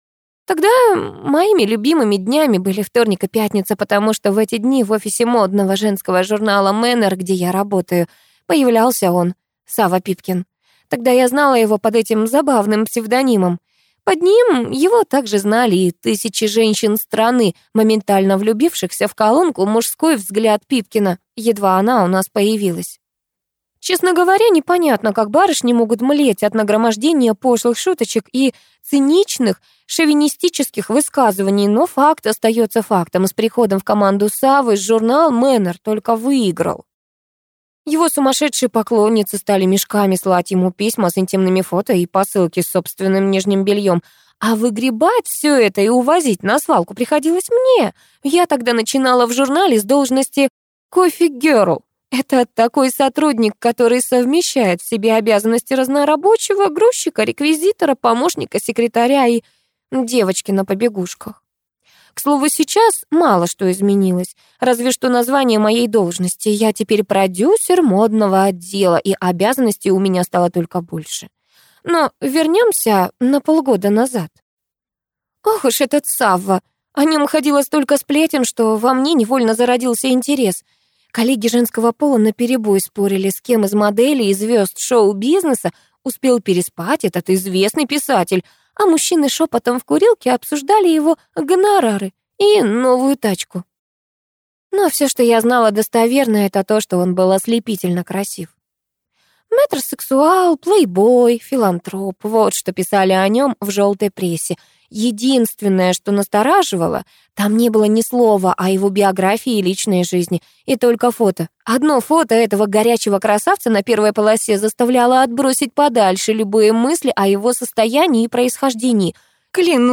Аудиокнига Игра в ненависть | Библиотека аудиокниг
Прослушать и бесплатно скачать фрагмент аудиокниги